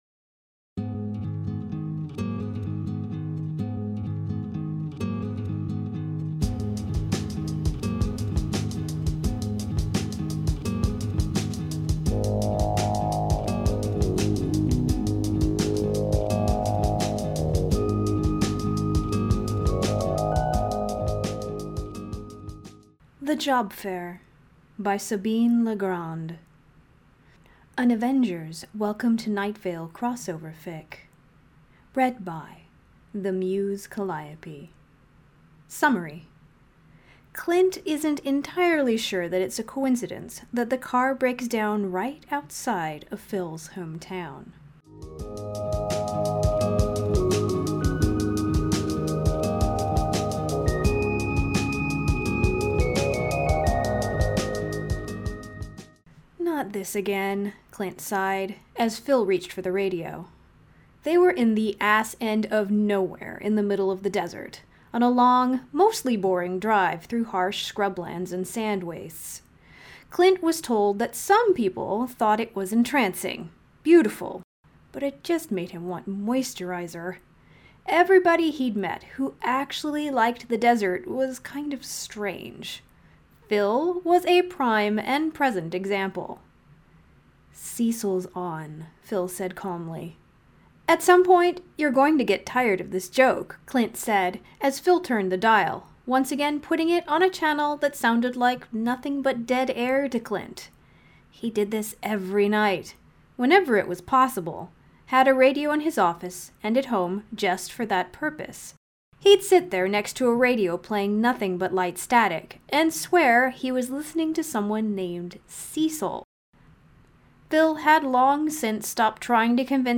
[Podfic] Job Fair